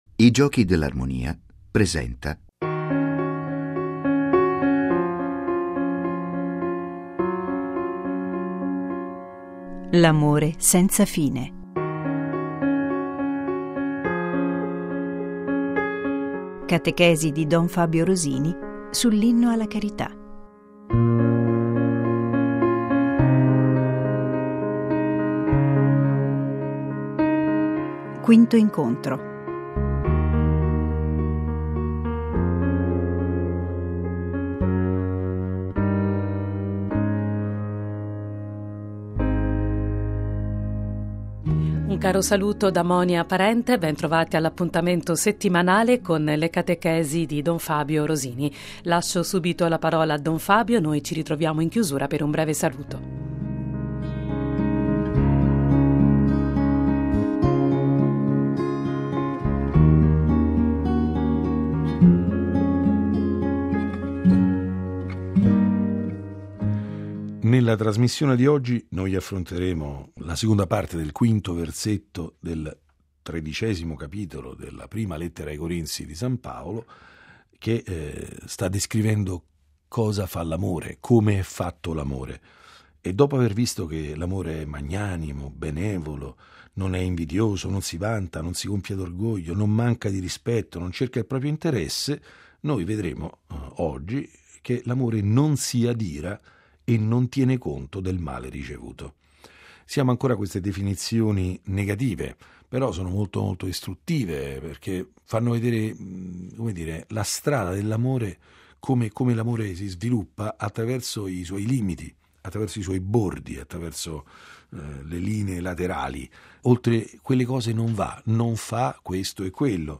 L'amore senza fine. Catechesi